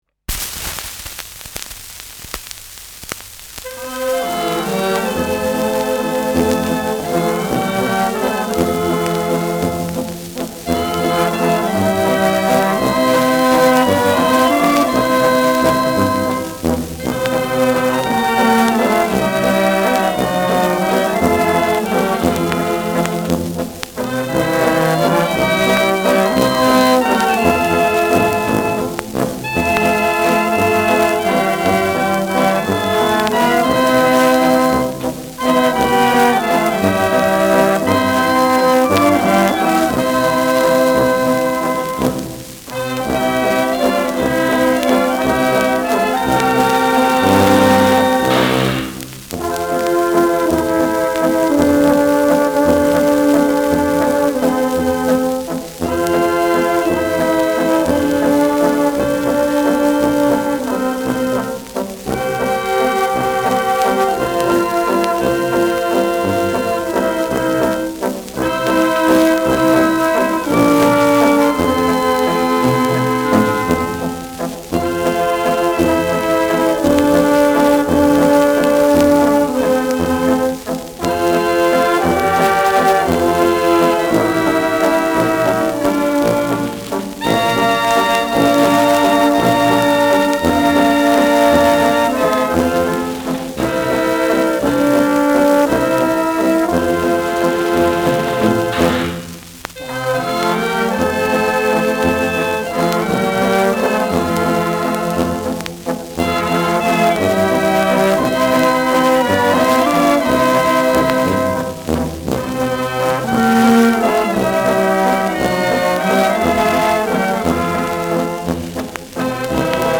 Schellackplatte
präsentes Rauschen : präsentes Knistern : leiert
[Berlin] (Aufnahmeort)